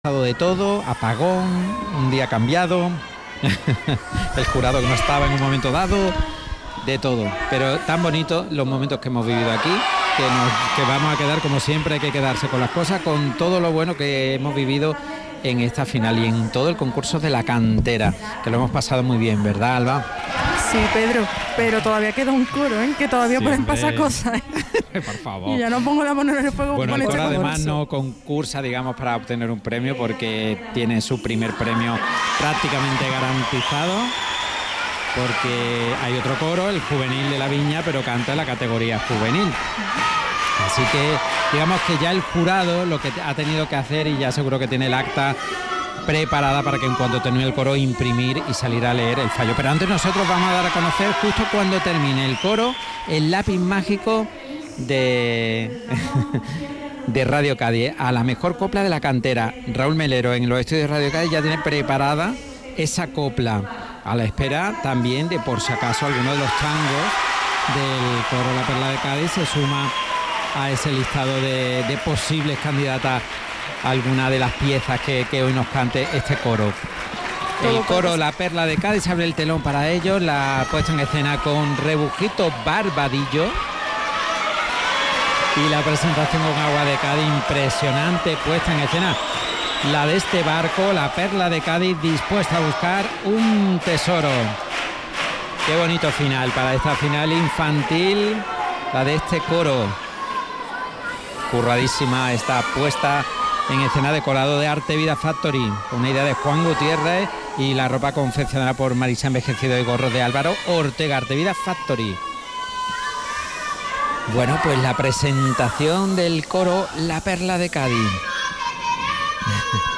Coro Infantil – La perla de Cádiz Final